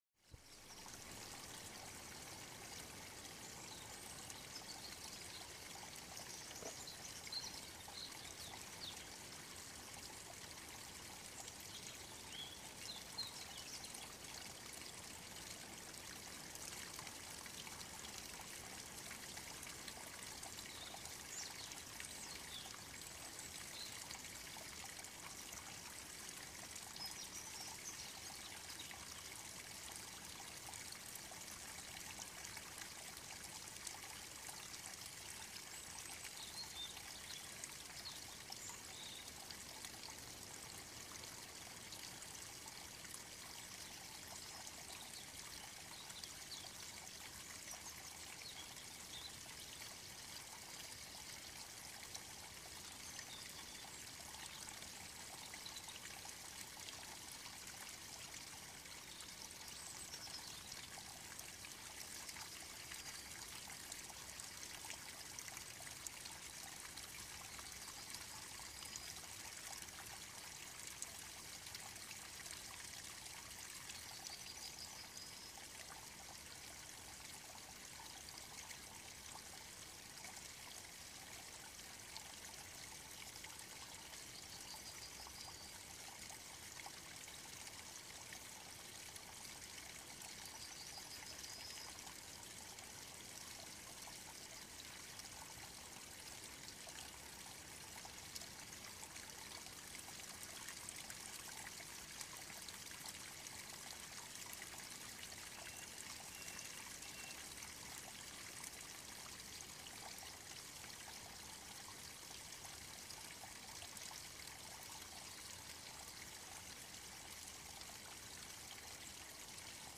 musique relaxation, chute d’eau
EFFETS SONORES DE LA NATURE
musique-relaxation-bruit-de-leau-lac-Bruits-Source-Chants-Oiseaux-Lac-de-montagne-RelaxAction.mp3